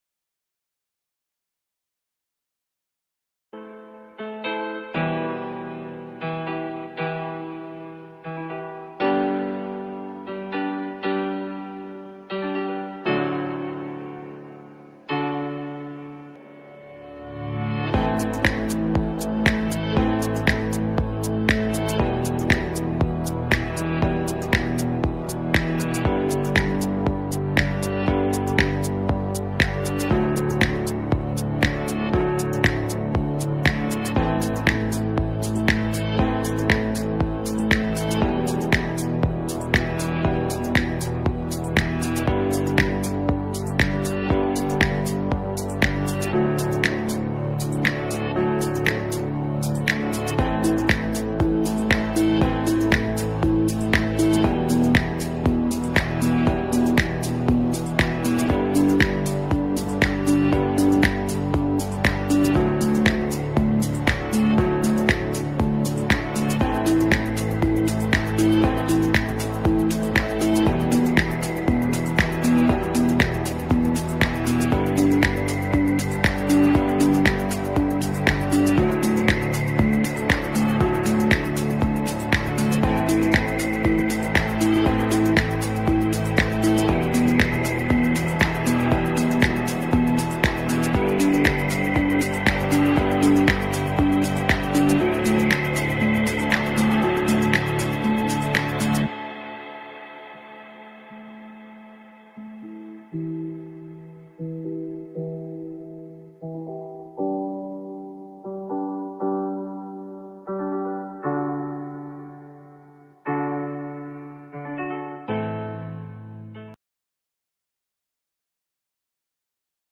This daily podcast could become an important part of your trading toolkit and is broadcast live to our traders every morning.